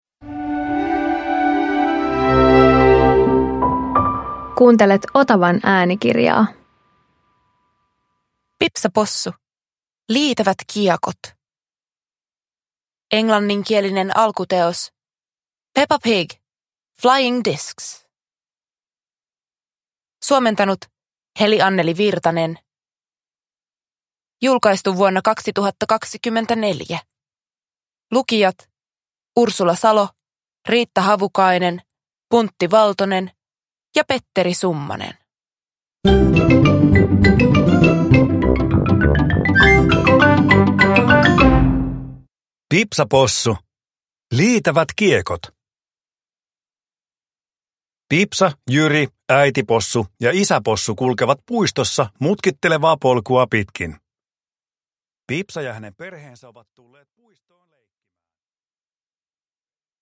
Pipsa Possu - Liitävät kiekot – Ljudbok